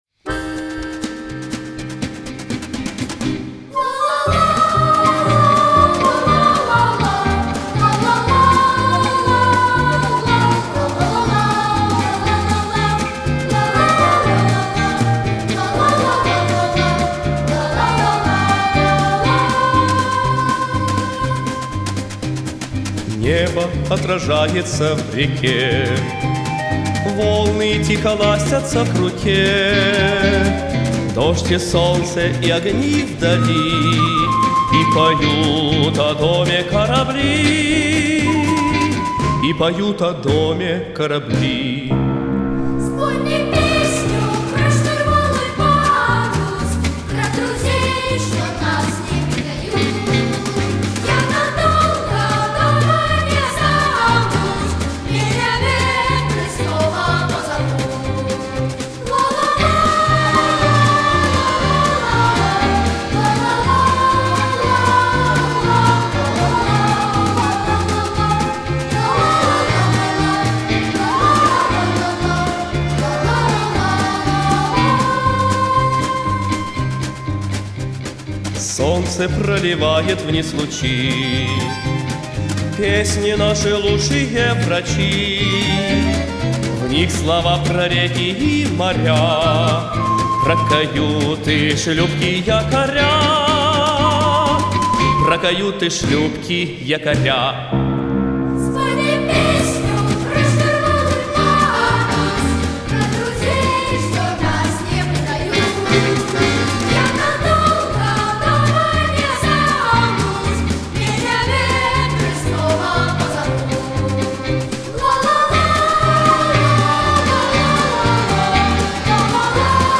Исполняет хор мальчиков.
Вокализ на «Ла».